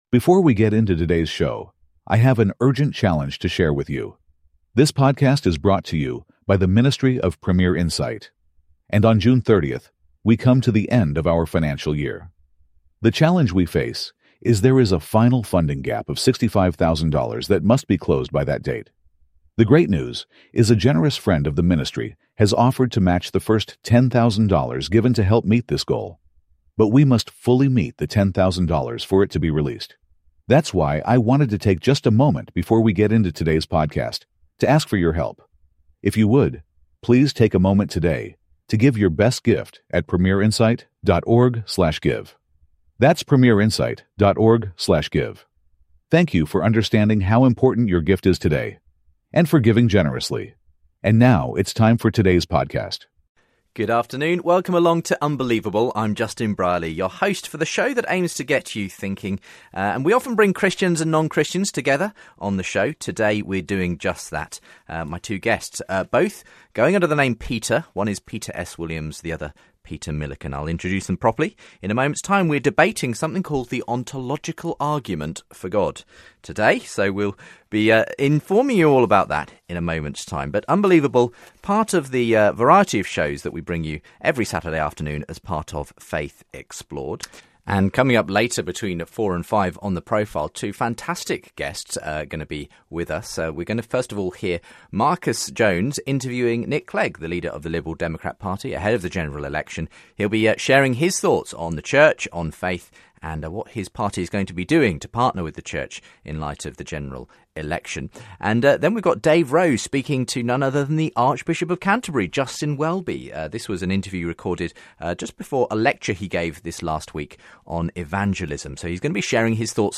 Christianity, Religion & Spirituality